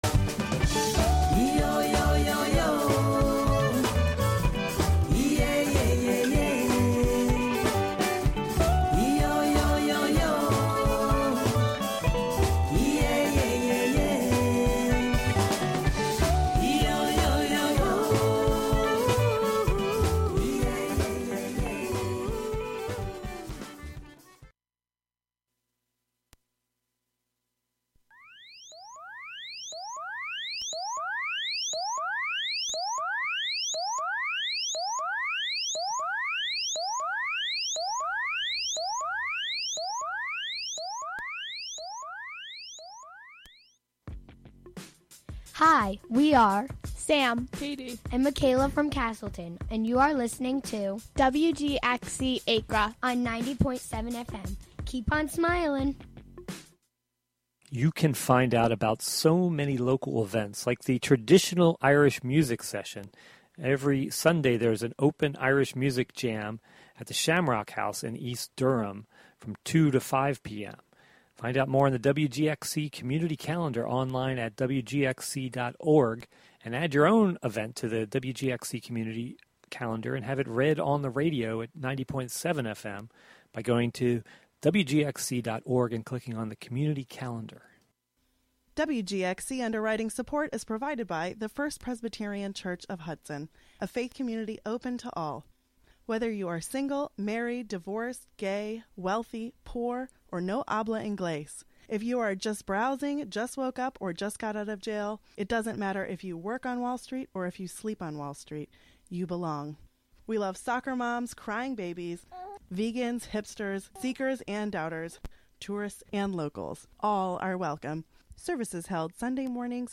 A singer, songwriter and musician, with only a trio for support she takes the samba of her native Brazil to new heights.